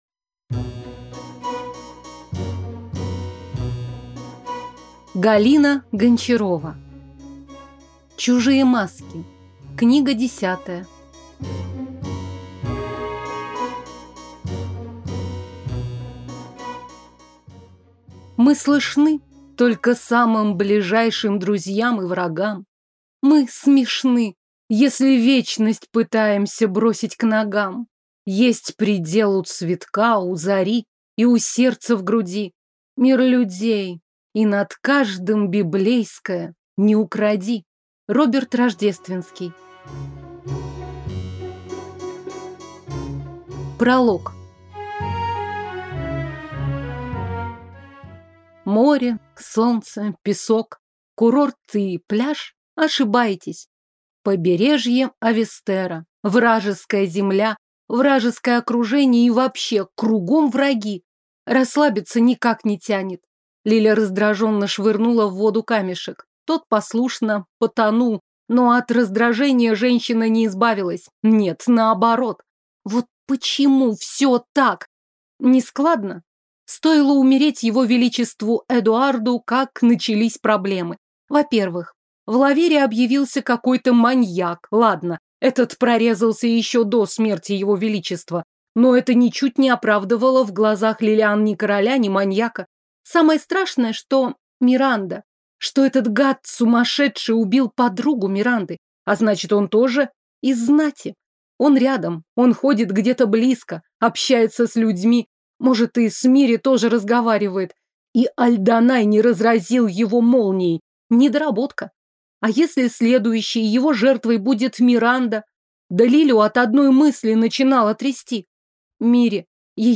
Аудиокнига Средневековая история. Чужие маски | Библиотека аудиокниг